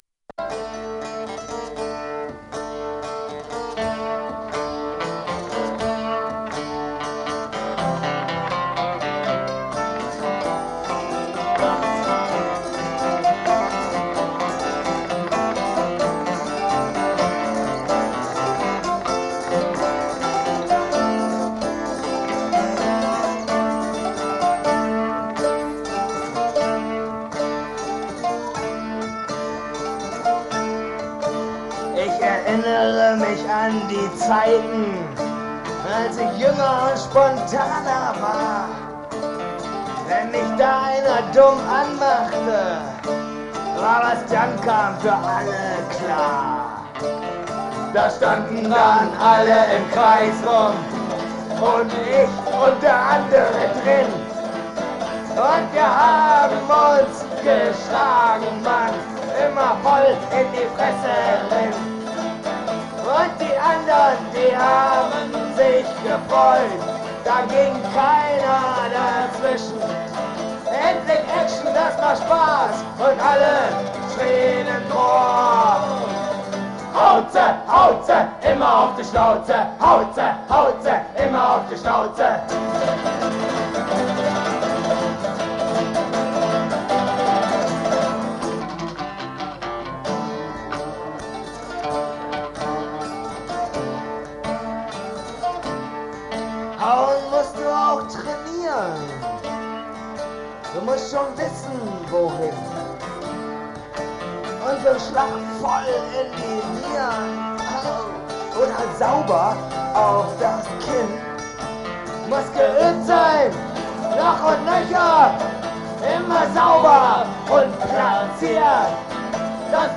live-Aufnahmen